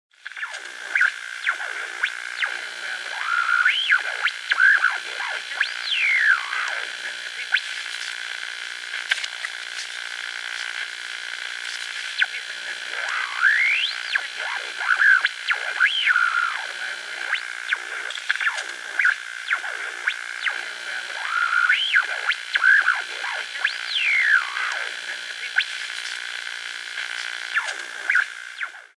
Звуки радио, помех
Свист радиопомех